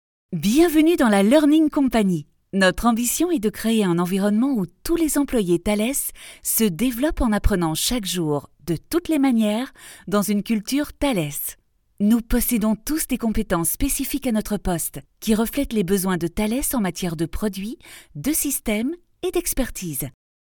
Commercial, Playful, Friendly, Versatile, Soft
E-learning